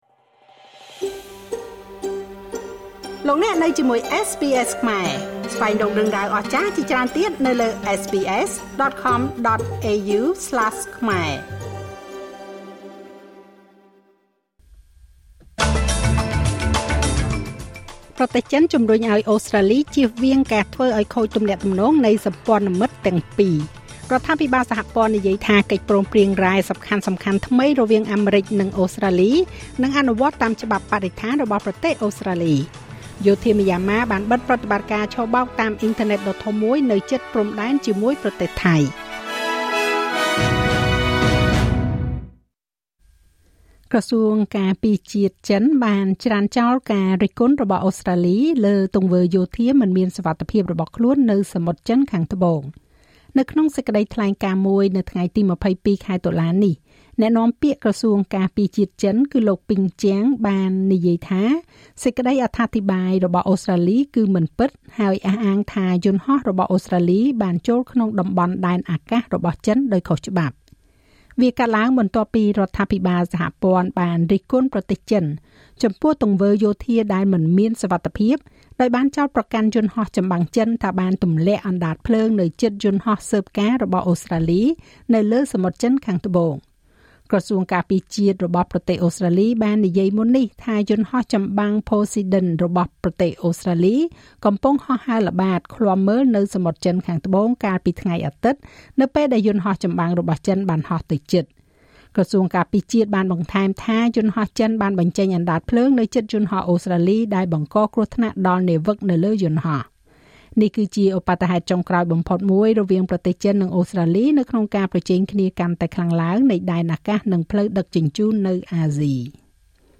នាទីព័ត៌មានរបស់SBSខ្មែរ សម្រាប់ថ្ងៃពុធ ទី២២ ខែតុលា ឆ្នាំ២០២៥